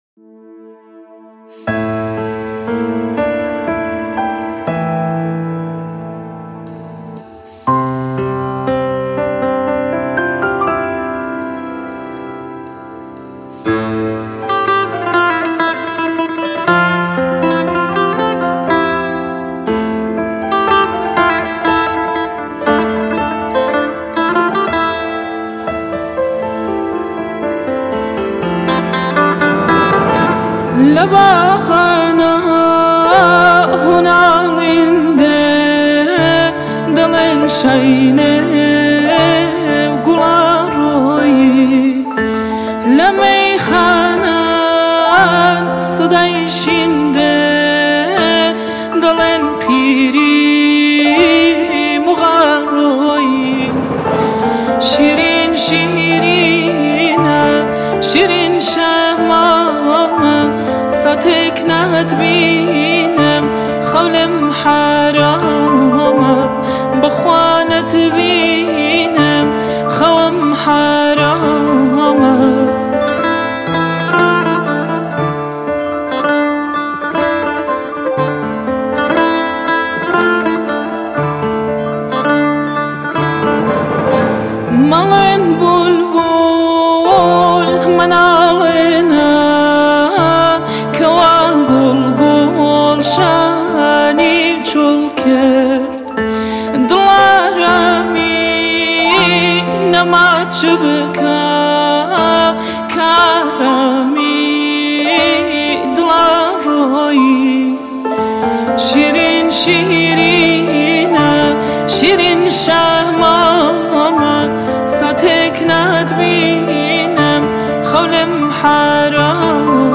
آهنگ کردی پاپ
آهنگ کردی شاد